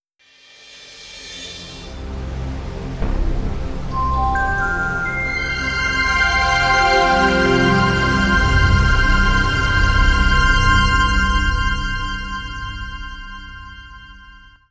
Windows NT 4.10 Startup.wav